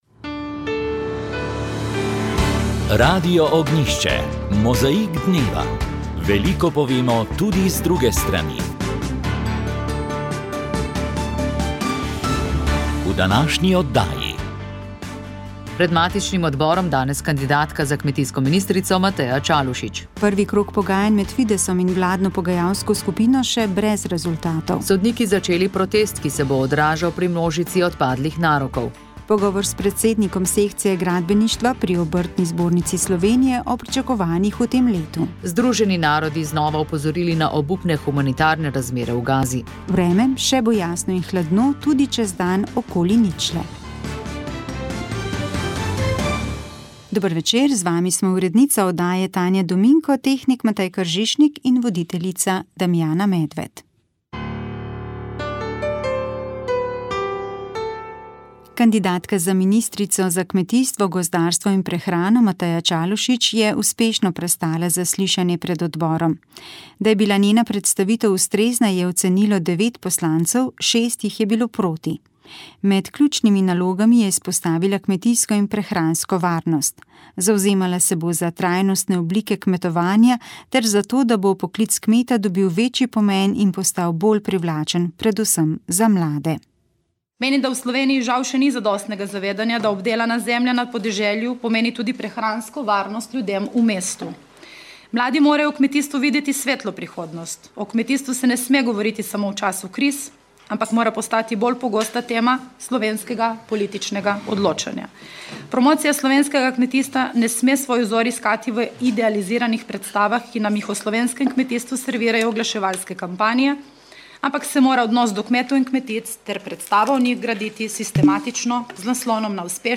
Slovenska podjetja pogosto izpostavljajo potrebo po boljšem davčnem okolju, ki bi vključevalo nižje davke na dobiček in spodbude za raziskave ter digitalizacijo. O tem so govorili v tokratnem »Pogovoru o« v katerega smo povabili predstavnike Gospodarske zbornice Slovenije, Obrtno-podjetniške zbornice Slovenije in Kluba Slovenskih podjetnikov.